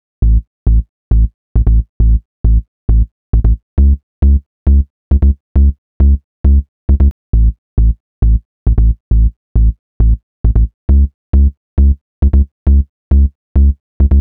TI CK7 135 Sub.wav